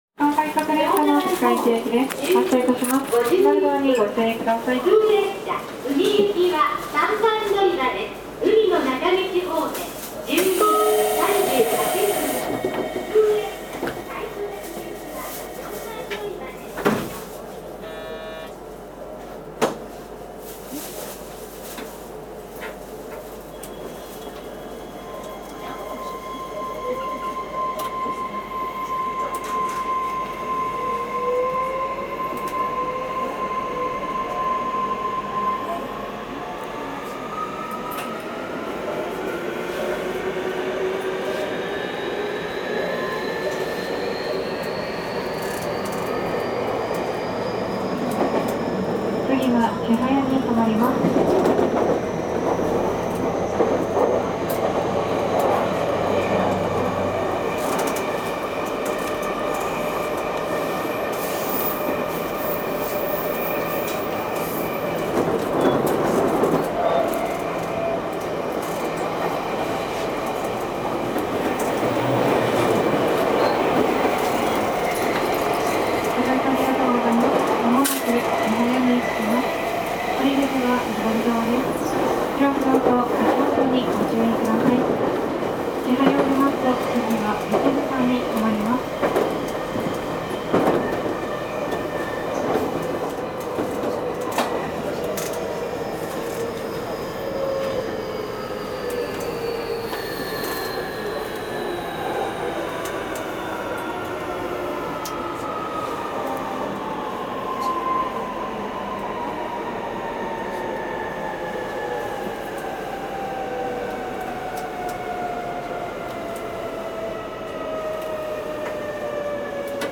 走行音
更新車(VVVFインバータ制御)
録音区間：香椎～千早(区間快速)(お持ち帰り)
811-VVVF.mp3